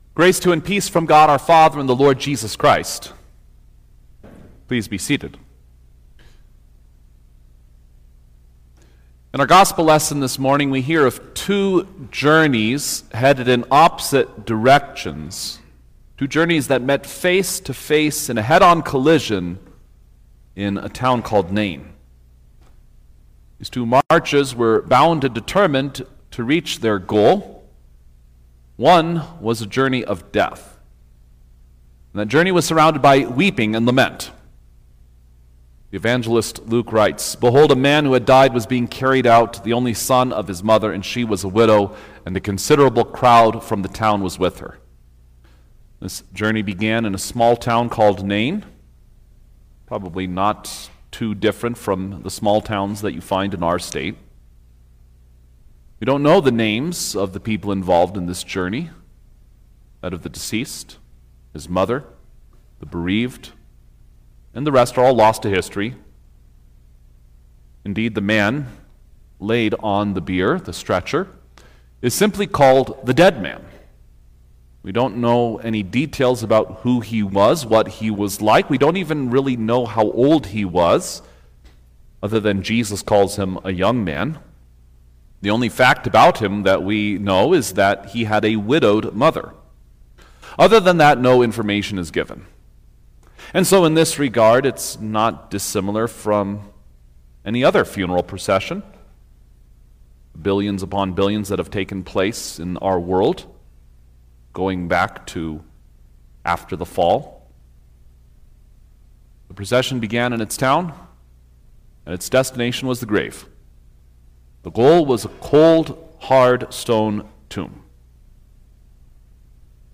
October-5_2025_Sixteenth-Sunday-after-Trinity_Sermon-Stereo.mp3